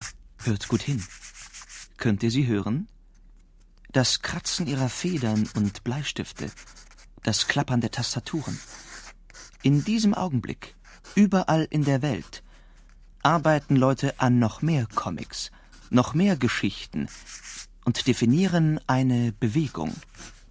Sprecher deutsch, seriös, sympathisch, mittelkräftig, facettenreich
Sprechprobe: eLearning (Muttersprache):